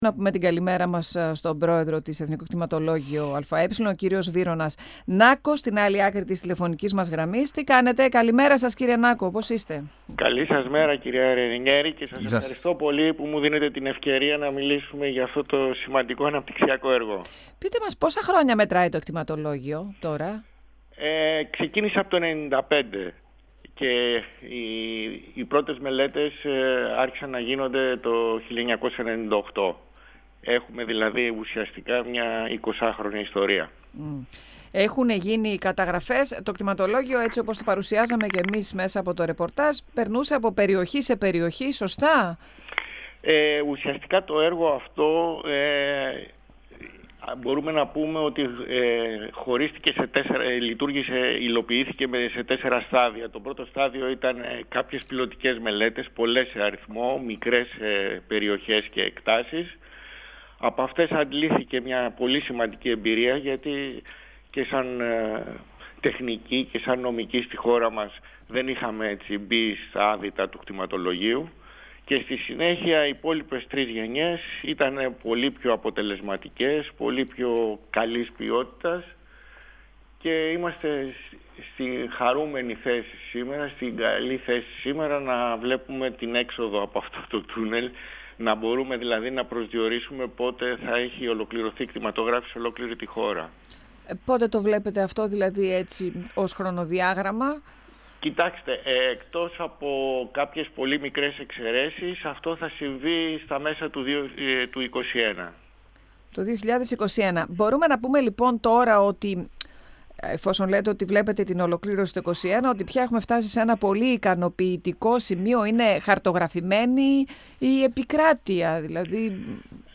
Βύρων Νάκος, μιλώντας στον 102FM του Ραδιοφωνικού Σταθμού Μακεδονίας της ΕΡΤ3. 102FM Συνεντεύξεις ΕΡΤ3